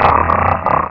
Cri de Grahyèna dans Pokémon Rubis et Saphir.